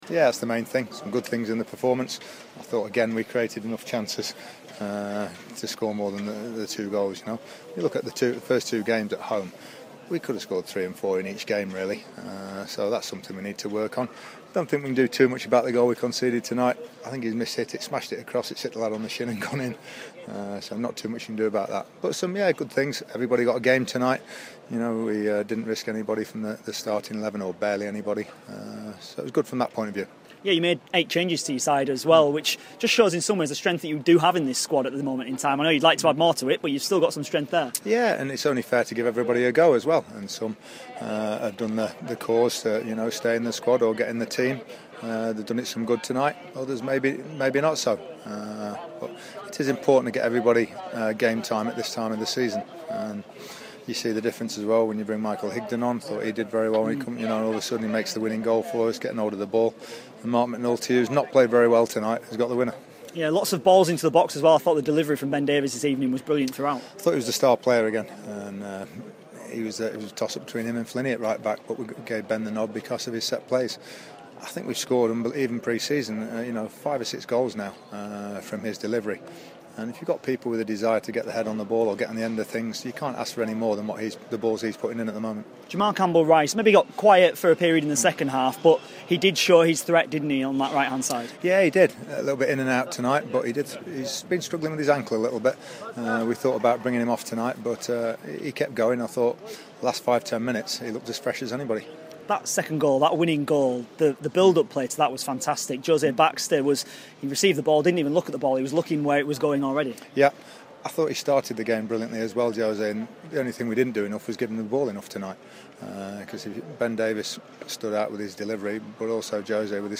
INTERVIEW: Sheffield United manager Nigel Clough reflects on their 2-1 win over Mansfield in the League Cup.